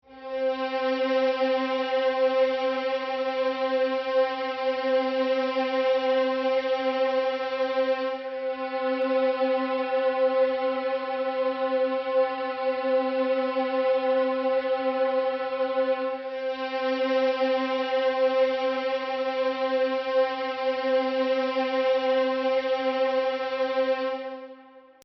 This means that the first part of the audio file (A) is a violin playing A (440 Hz), the second part (B) is the same violin sound but with a slight application of frequency compression where the higher frequency harmonics (above 1500 Hz) are only decreased by one half of one semi-tone, and the third part (A) is the original unaltered violin again, for comparison.
Violin half semitone flat
Violin_half_semitone_flat_ABA.mp3